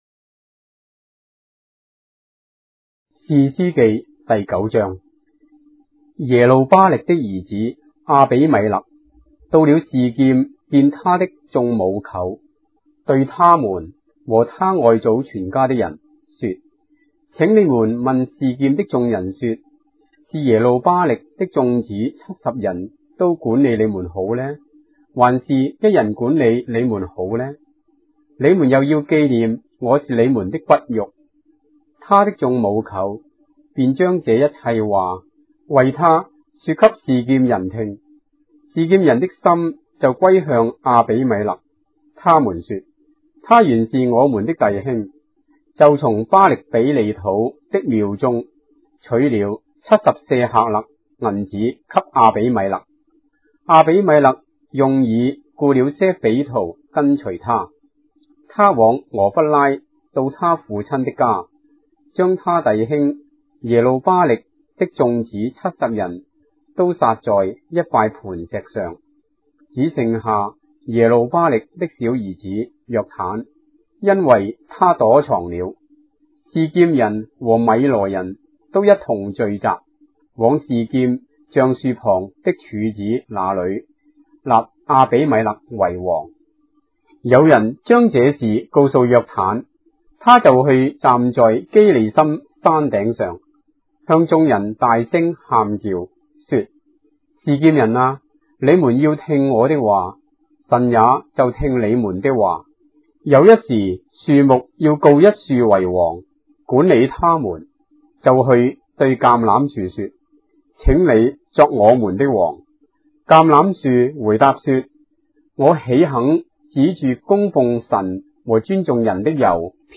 章的聖經在中國的語言，音頻旁白- Judges, chapter 9 of the Holy Bible in Traditional Chinese